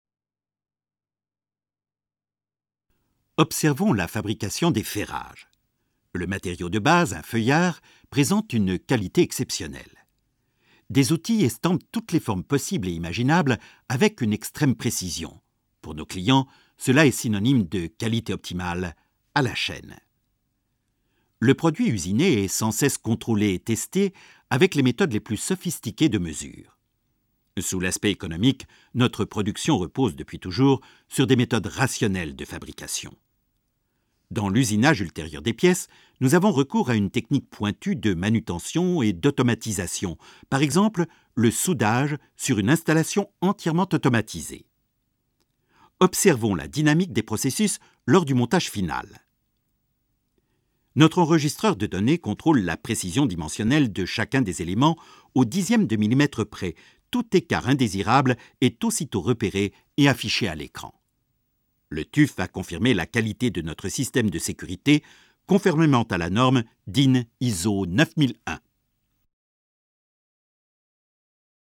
Kein Dialekt
Sprechprobe: Industrie (Muttersprache):
french voice over artist.